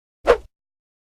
whoosh.mp3